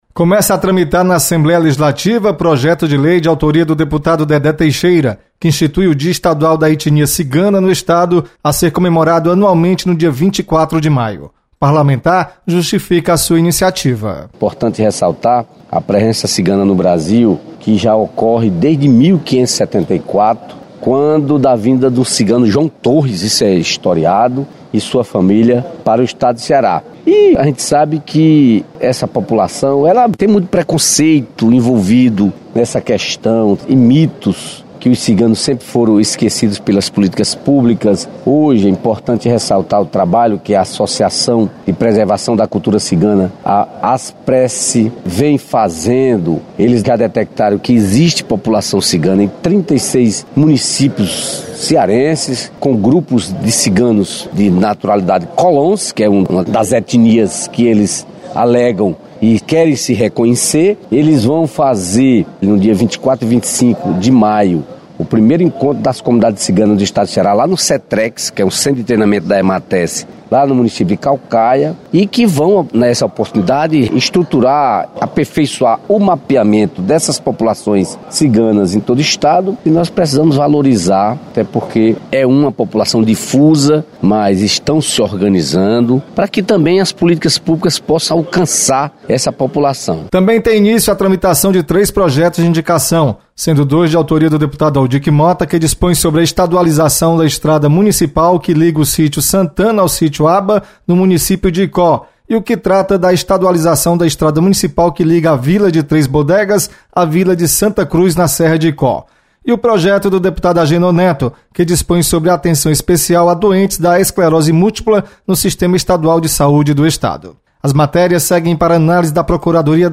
Deputado Dedé Teixeira propõe projeto de lei para instituir o Dia da etnia Cigana. Repórter